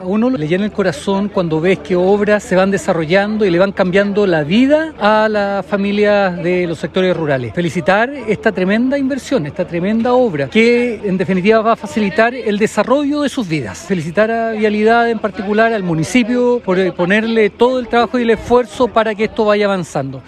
La ceremonia se desarrolló este miércoles con la presencia de autoridades regionales y locales.
Diputado-Marcos-ilabaca.mp3